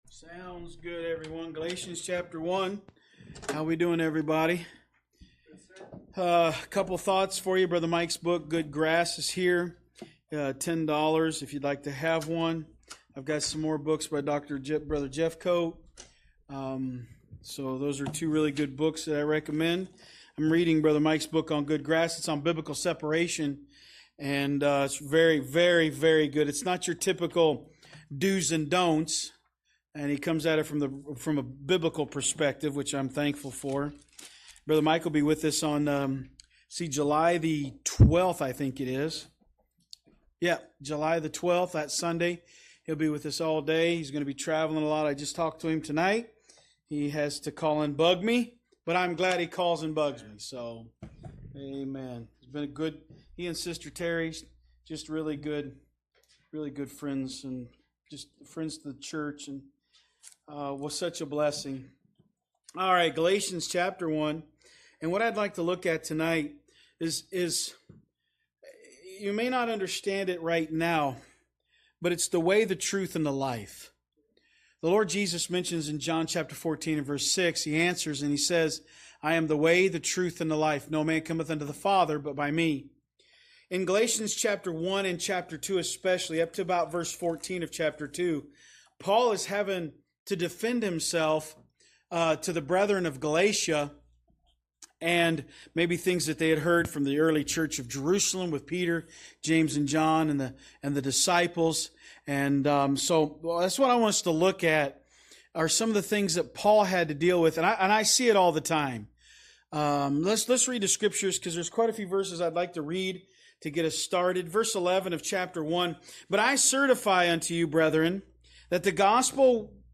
From Series: "Wednesday Service"